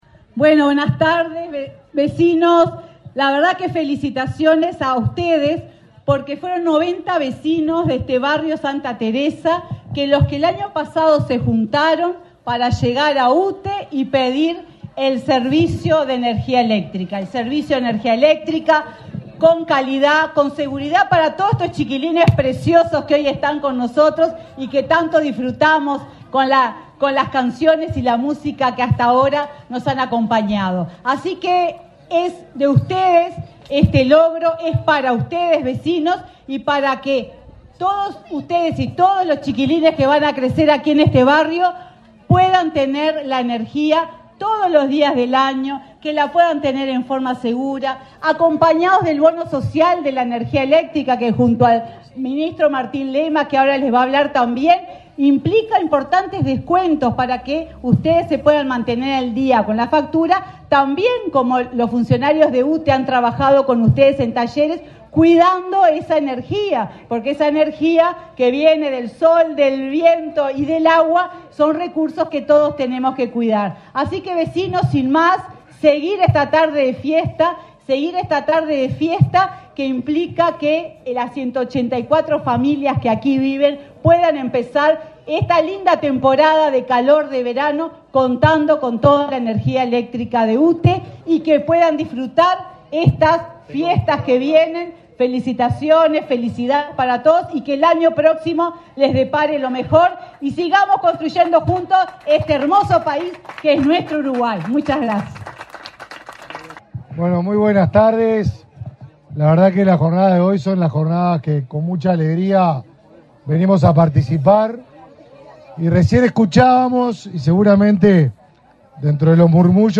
Conferencia de prensa por la inauguración de obras en Montevideo
La empresa UTE y el Ministerio de Desarrollo Social (Mides) inauguraron obras de electrificación correspondientes al Programa de Inclusión Social, este 9 de diciembre, las cuales beneficiarán a 89 familias residentes del Barrio Santa Teresa de Montevideo. Participaron del evento el ministro del Mides, Martín Lema, y la presidenta de UTE, Silvia Emaldi.